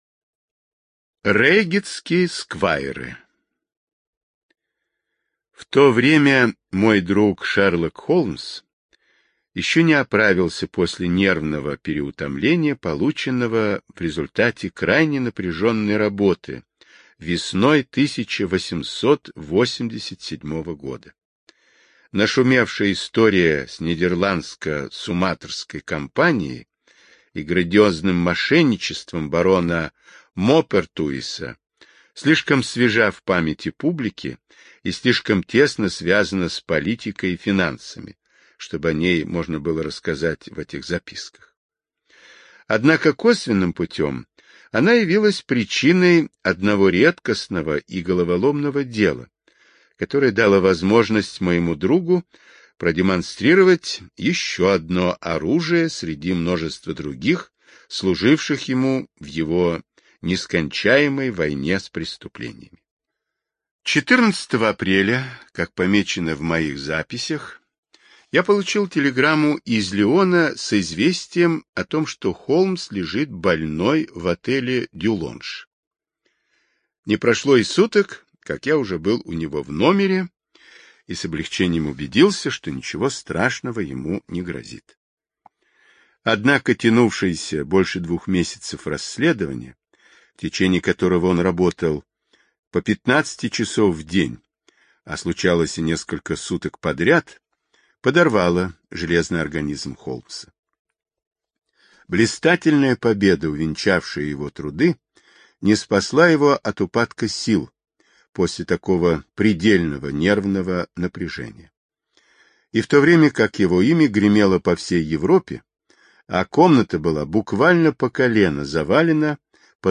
Рейгетские сквайры — слушать аудиосказку Артур Конан Дойл бесплатно онлайн